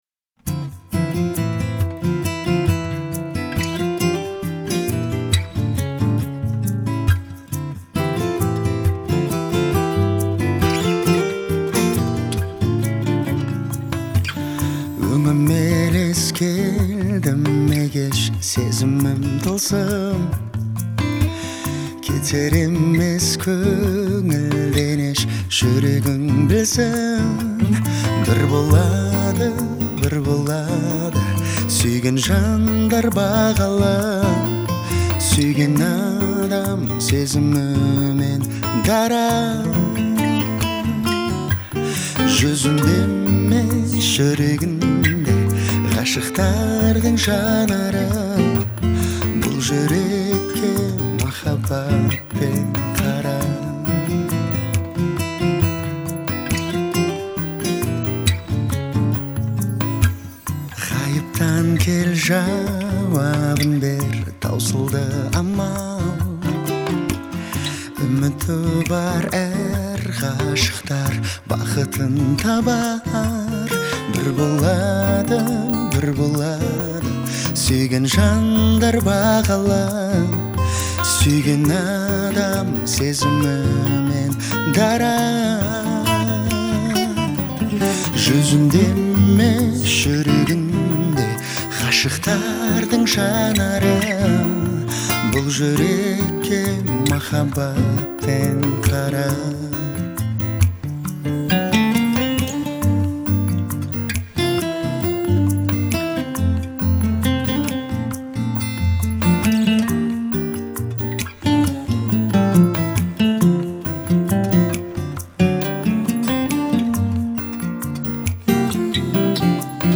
В ней царит позитивное и вдохновляющее настроение.